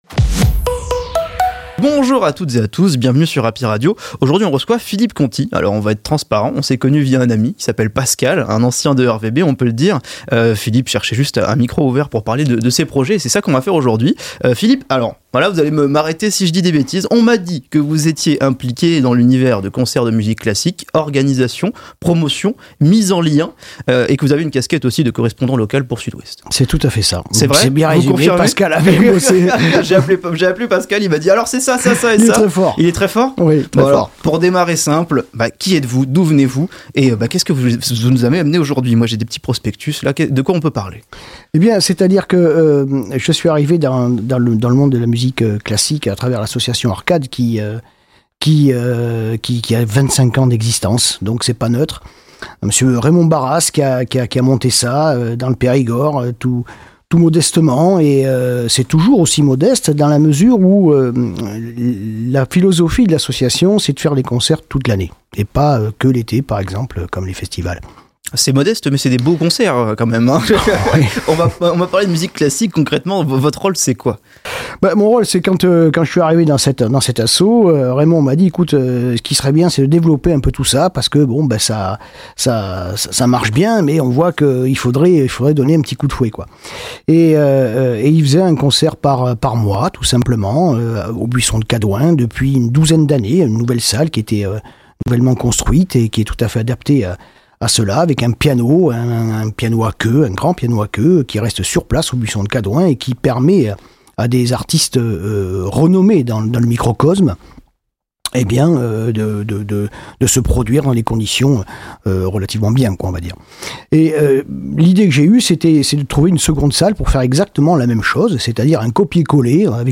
Les interviews Happy Radio